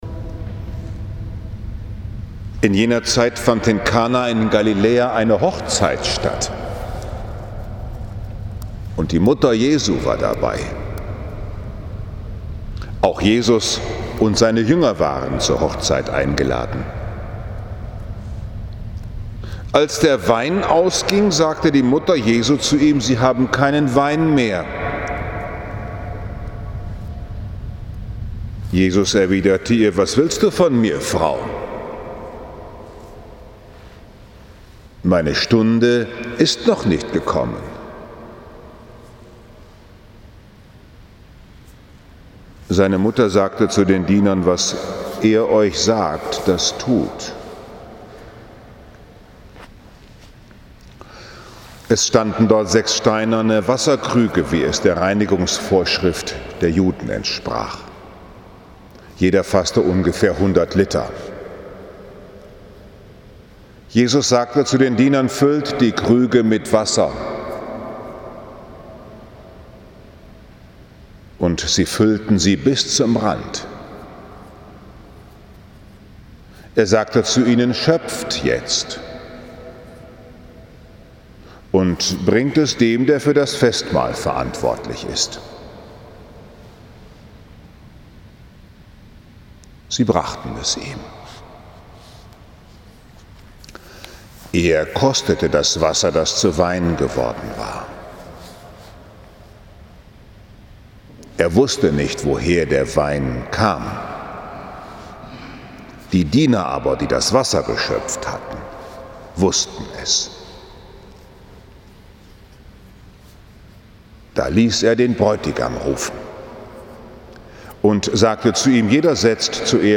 Verkündigung des Evangeliums Joh 2, 1-11